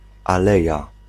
Ääntäminen
Synonyymit allée Ääntäminen France: IPA: [y.n‿a.və.ny] Tuntematon aksentti: IPA: /a.v(ə.)ny/ IPA: /av.ny/ Haettu sana löytyi näillä lähdekielillä: ranska Käännös Ääninäyte Substantiivit 1. aleja {f} Suku: f .